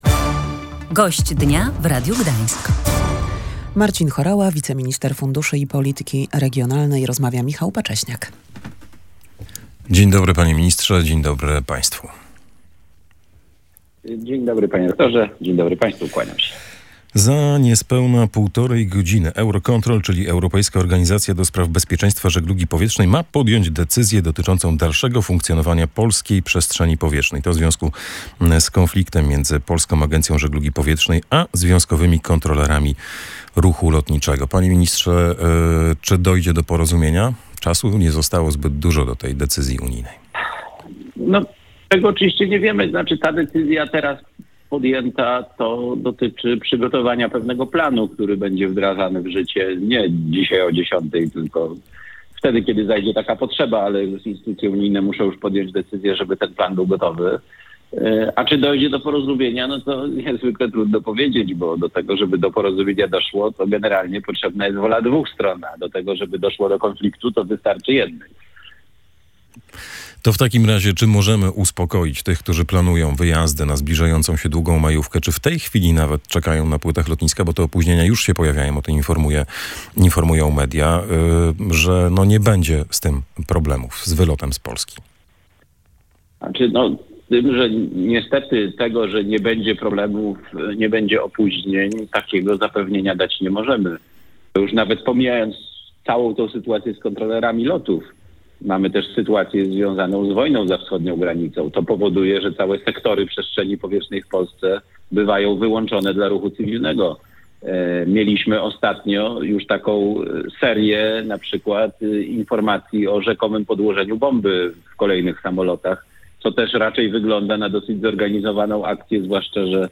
Rozmowa rozpoczęła się od pytań o strajk kontrolerów lotów i możliwe utrudnienia w poruszaniu się drogą powietrzną, np. w okresie weekendu majowego.